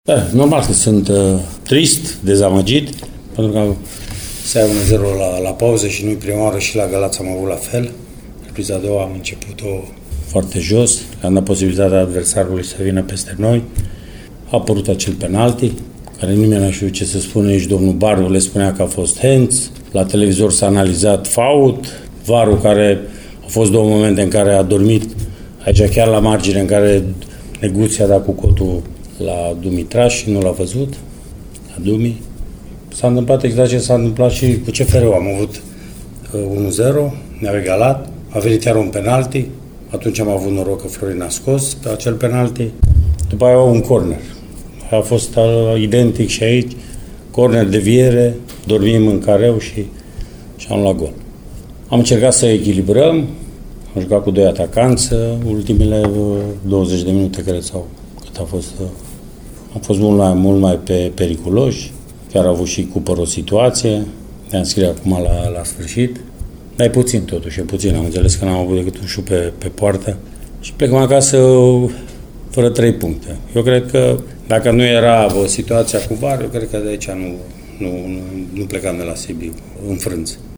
Antrenorul Mircea Rednic s-a declarat dezamăgit de rezultat și supărat pe arbitri: